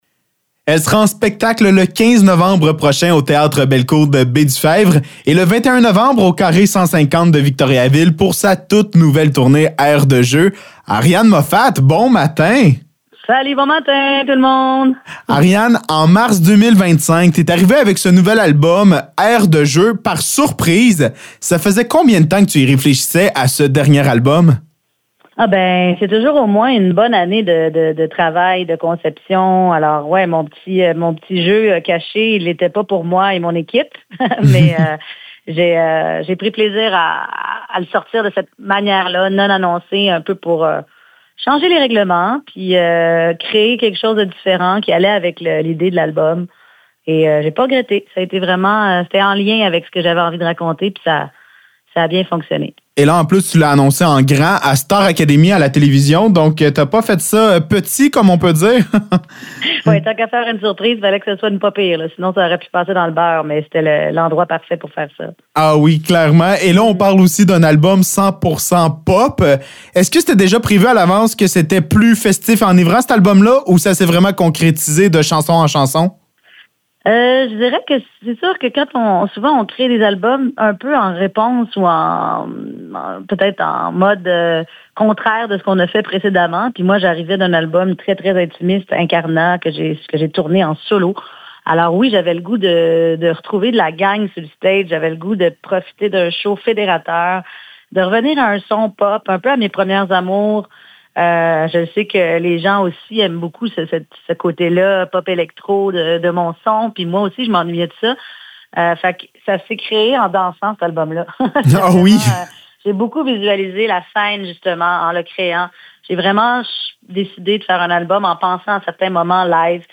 Entrevue avec Ariane Moffatt
ENTREVUE-ARIANE-MOFFATT.mp3